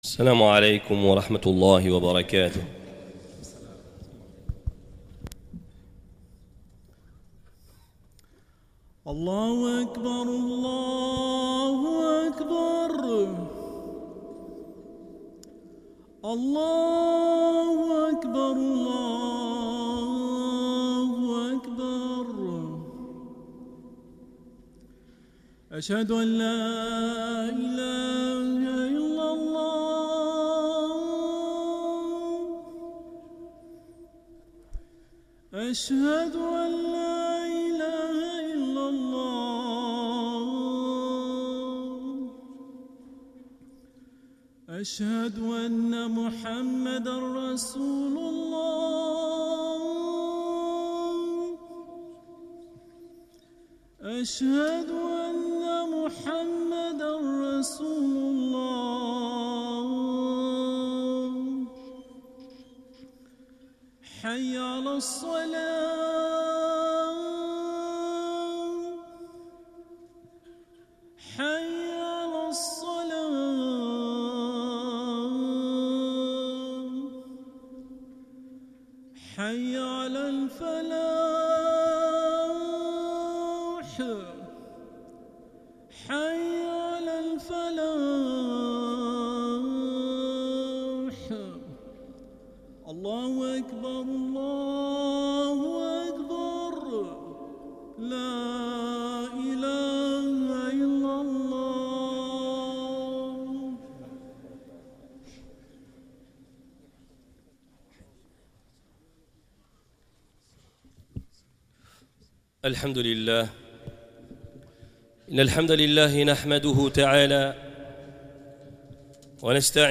Les prêches du Vendredi